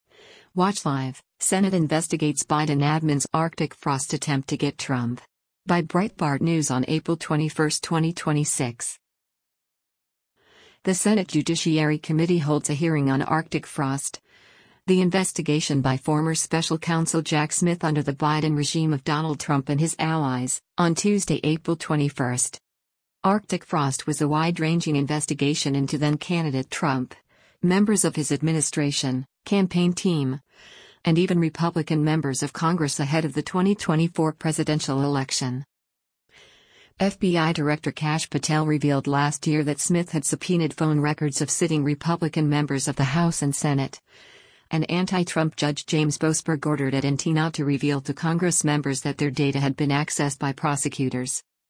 The Senate Judiciary Committee holds a hearing on “Arctic Frost,” the investigation by former Special Counsel Jack Smith under the Biden regime of Donald Trump and his allies, on Tuesday, April 21.